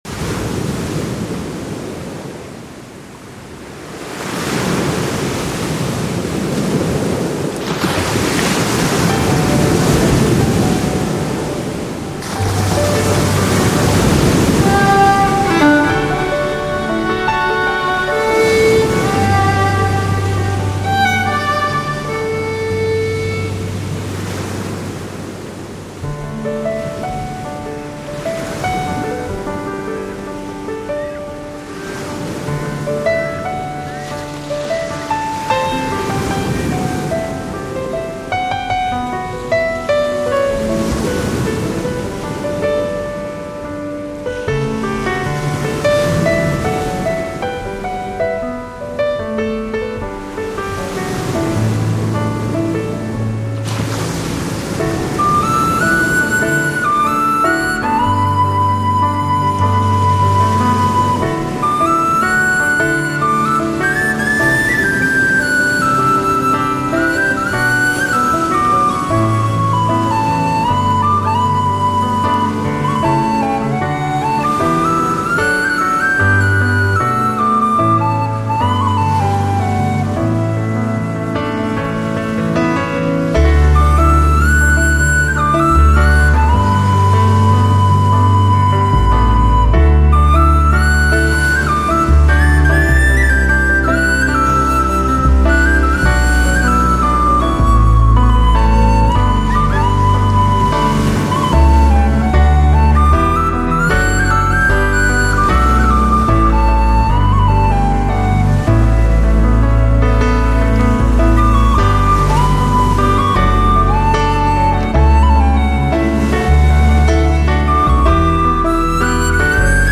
再探克爾特音樂秘境，拾回心靈田畝的純真淨土
實地收錄克爾特境內的空間人文音響